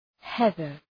Προφορά
{‘heðər}